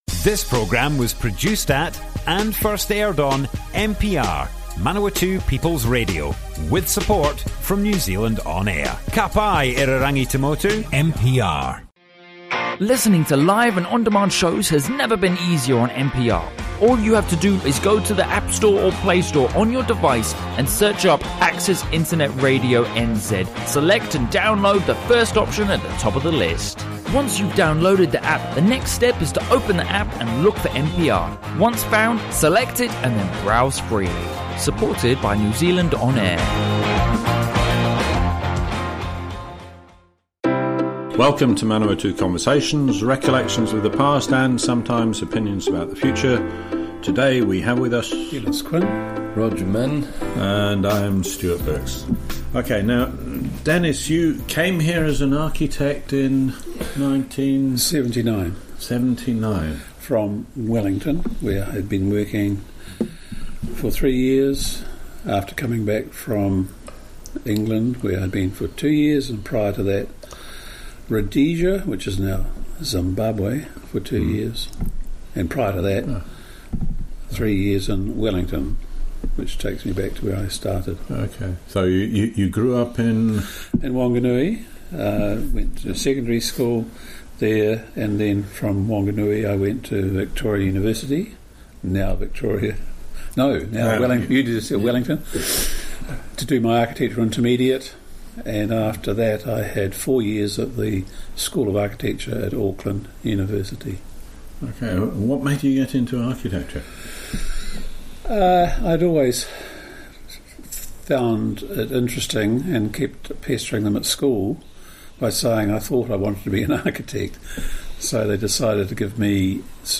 Broadcast on Manawatu People's Radio 20 November 2018.